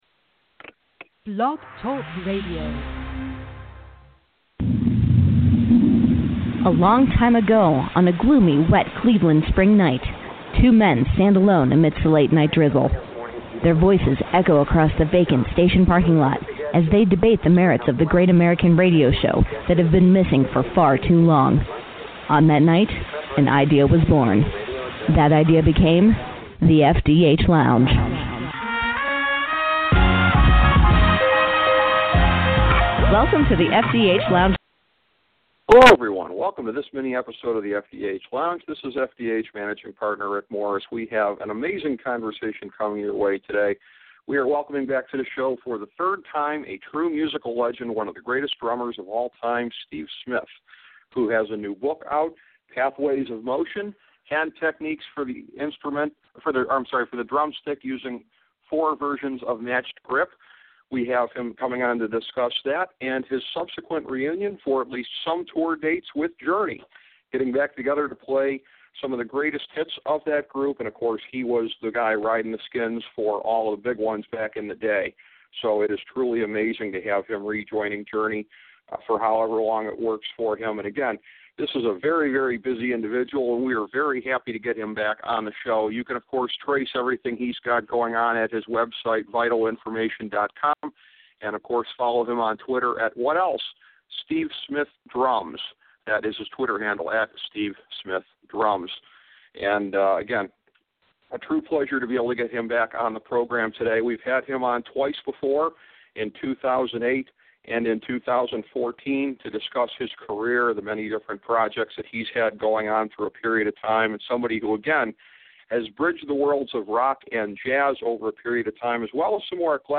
A conversation with Steve Smith